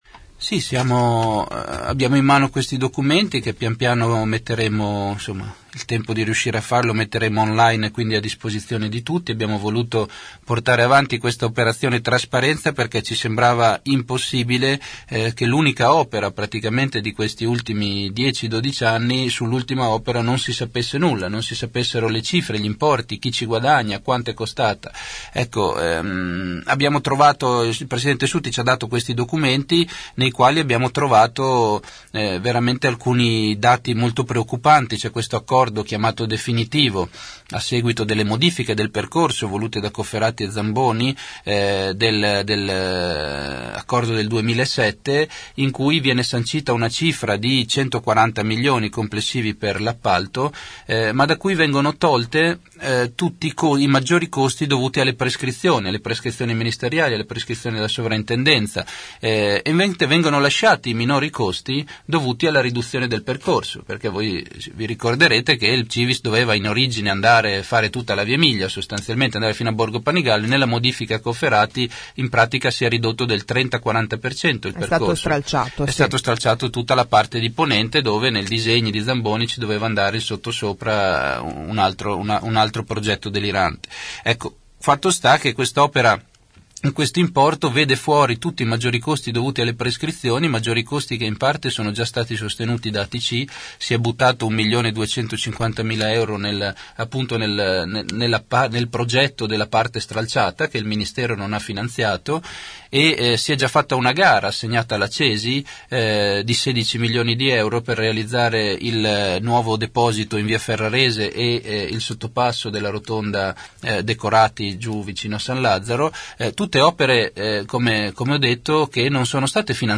Il candidato civico ne ha parlato nei nostri studi rispondendo alle domande degli ascoltatori.